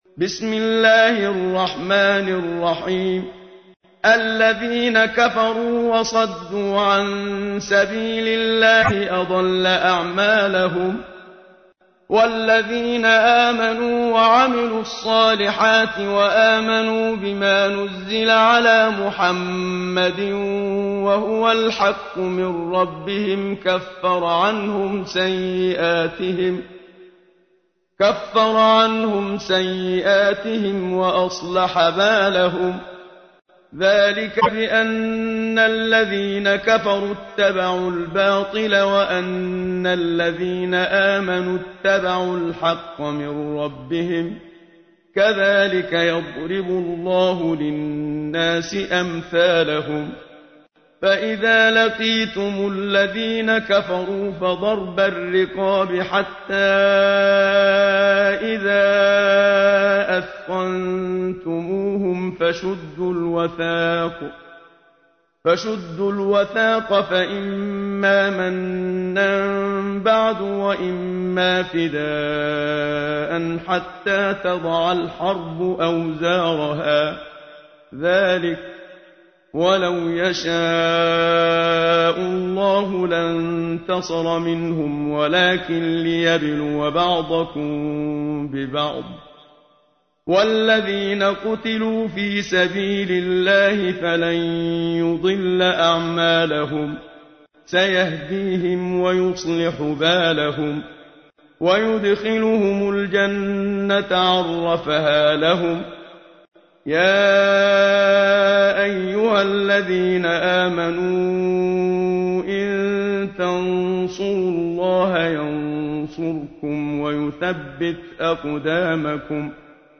تحميل : 47. سورة محمد / القارئ محمد صديق المنشاوي / القرآن الكريم / موقع يا حسين